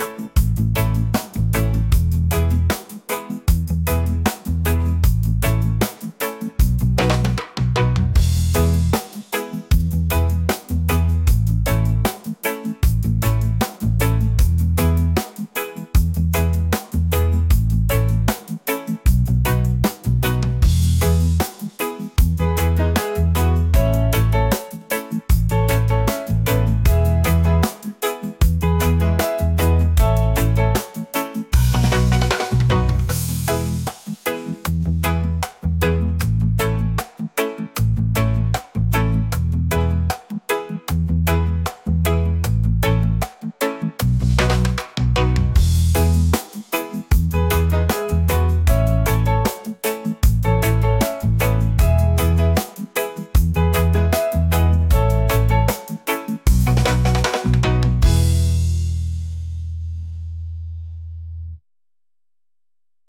upbeat | reggae | positive | vibes